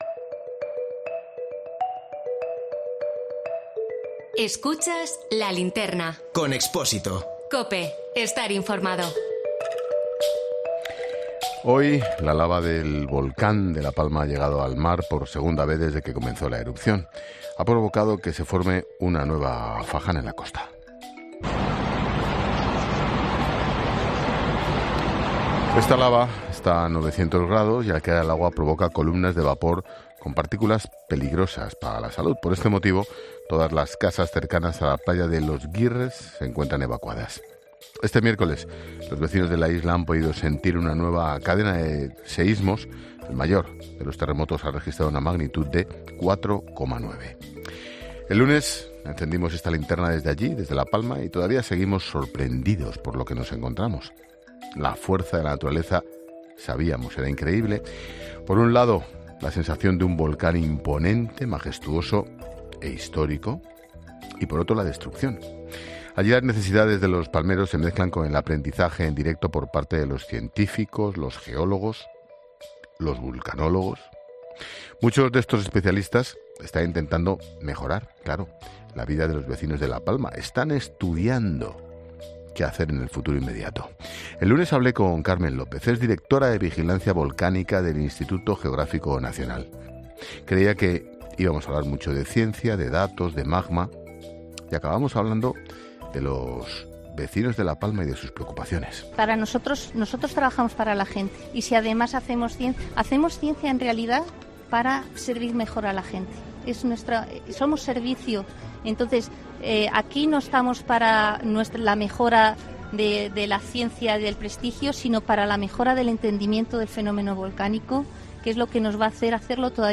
El lunes, encendimos la linterna desde allí, desde La Palma y todavía seguimos sorprendidos por lo que nos encontramos.